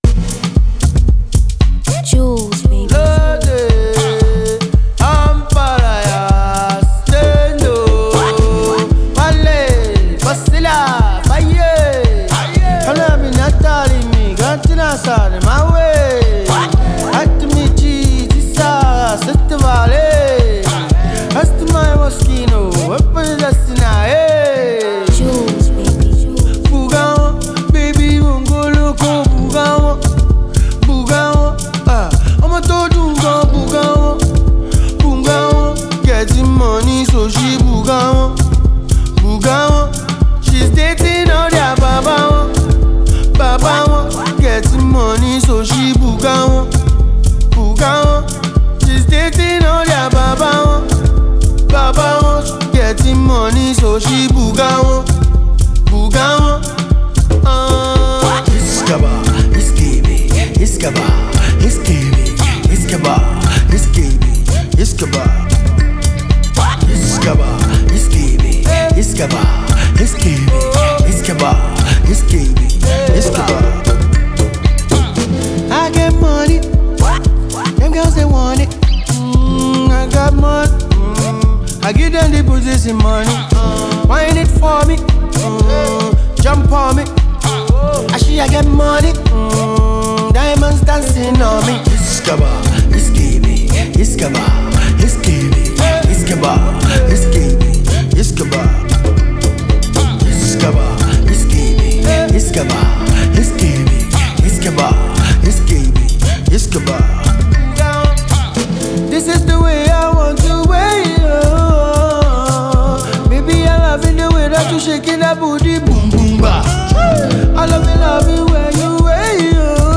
giving us a different kind of type of tune to our Afrobeat.
melodious song